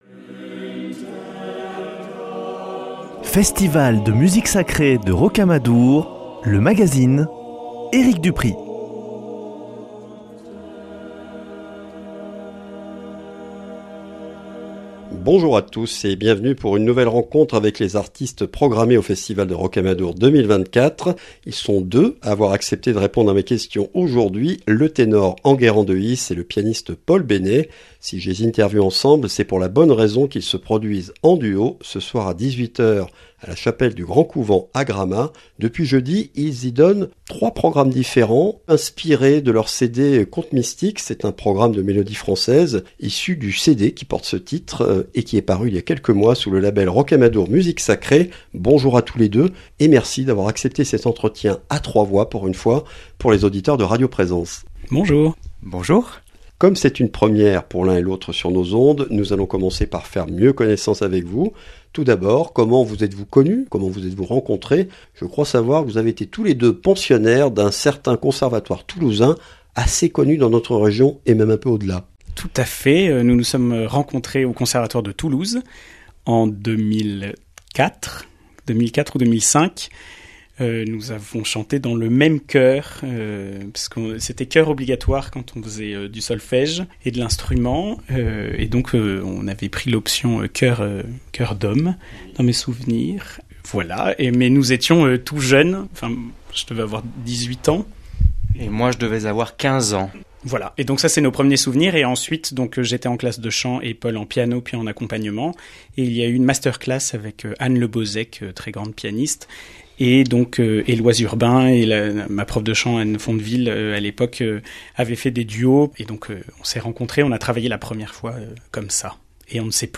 Ce disque a largement alimenté leur série de récitals au Grand Couvent de Gramat, le dernier dédié À Marie ayant lieu ce samedi à 18 h. Discussion autour de leur longue amitié musicale et du programme de leur CD.